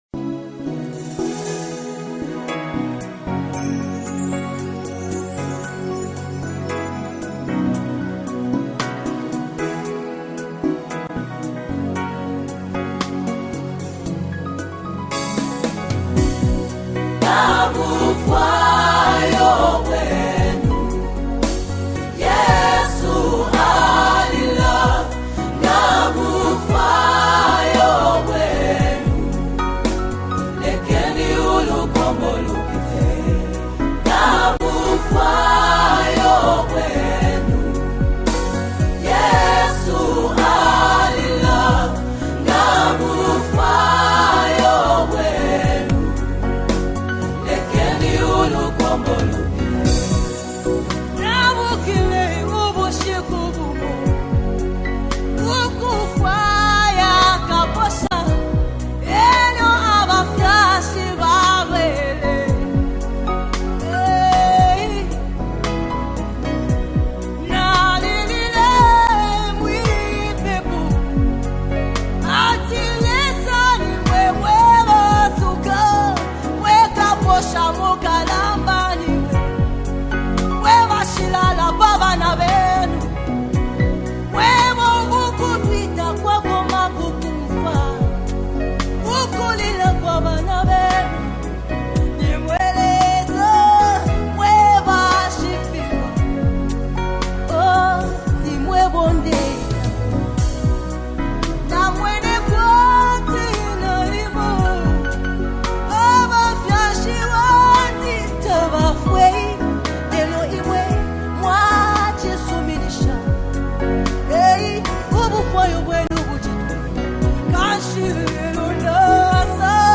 continues to bless his fans with soul-stirring gospel music
soothing and heartfelt delivery